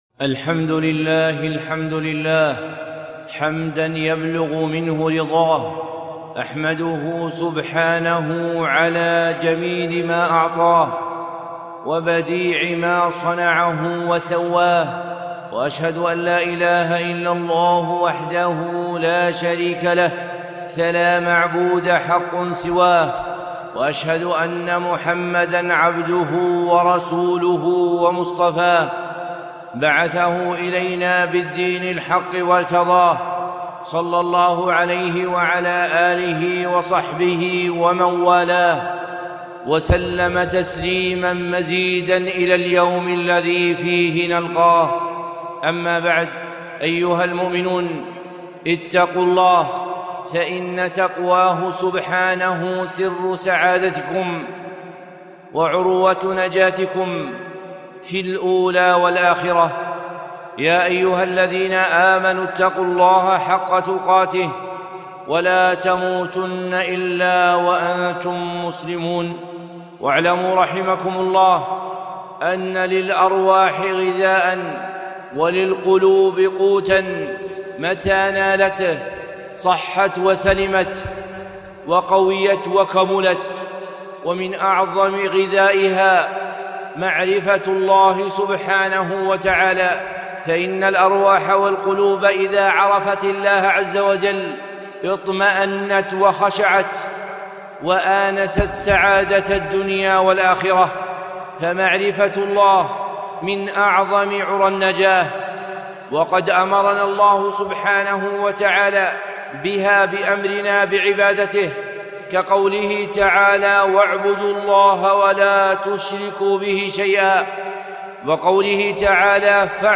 خطبة - معرفة الله بأسمائه الحسنى